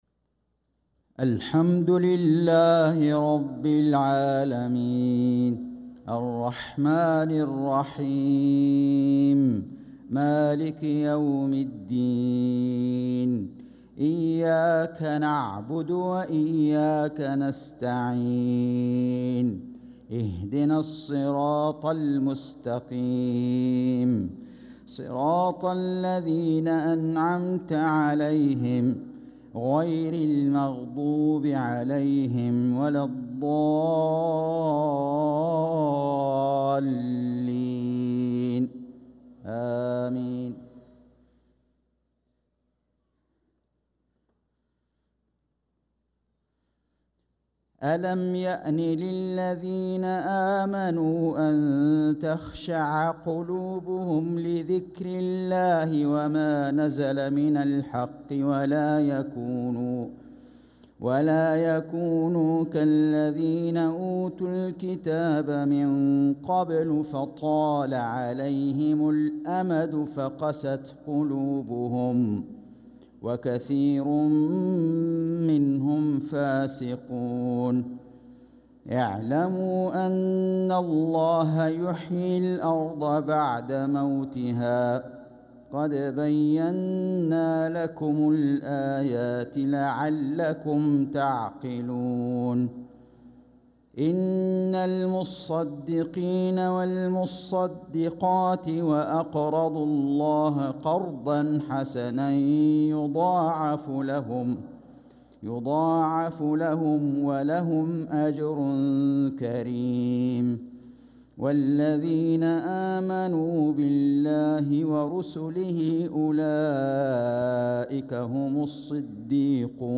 صلاة المغرب للقارئ فيصل غزاوي 24 جمادي الأول 1446 هـ
تِلَاوَات الْحَرَمَيْن .